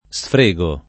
sfrego [ S fr %g o ], ‑ghi